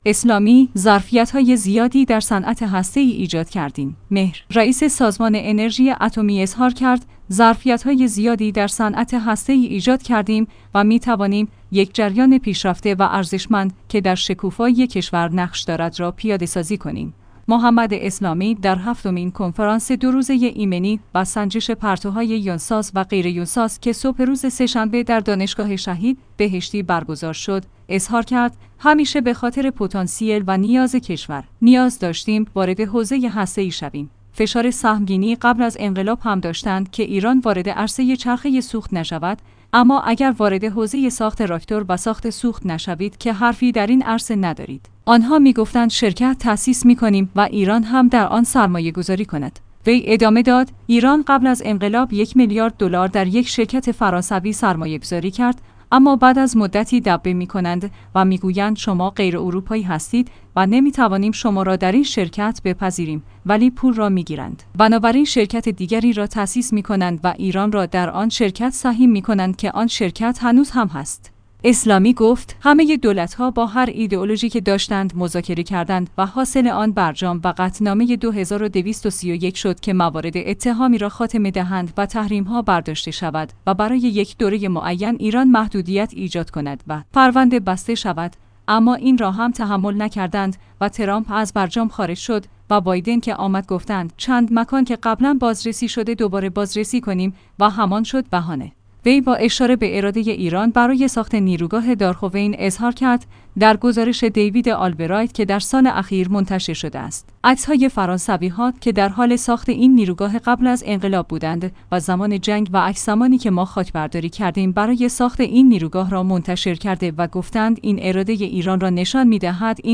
«محمد اسلامی» در هفتمین کنفرانس ۲ روزه ایمنی و سنجش پرتوهای یونساز و غیریونساز که صبح روز سه‌شنبه در دانشگاه شهید بهشتی برگزار شد،